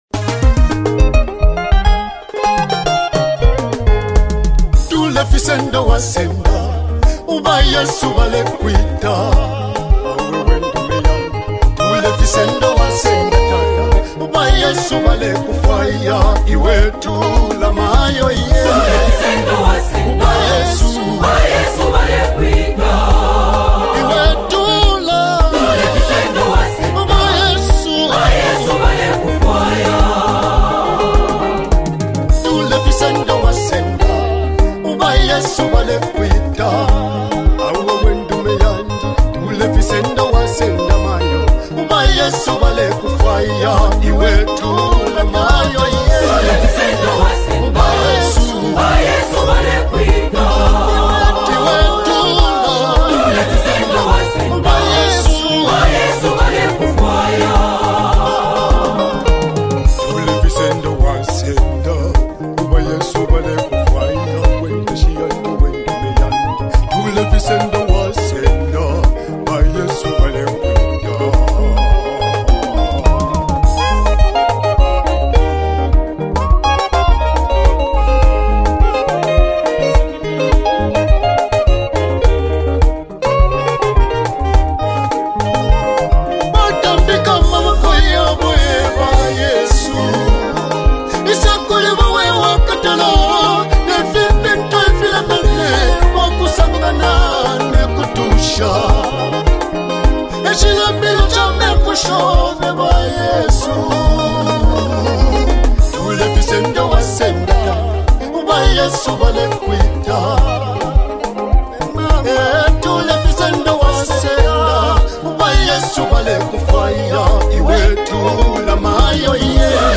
PEACEFUL WORSHIP SUPPLICATION | 2025 ZAMBIA GOSPEL
a calming and heartfelt WORSHIP ANTHEM